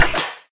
laser-beam-end-1.ogg